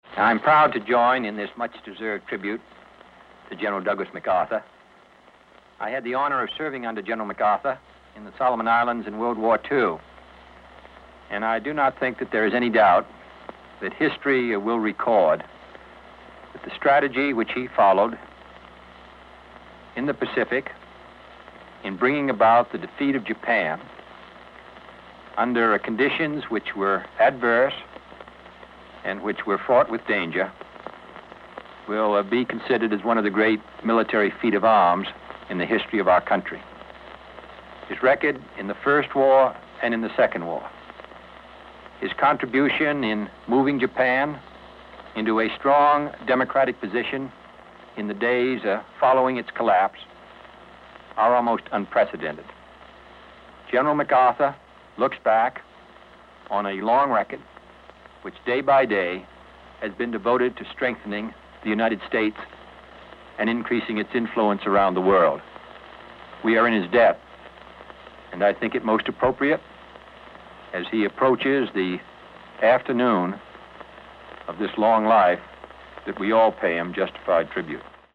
Audio mp3 of Address       Audio AR-XE mp3 of Address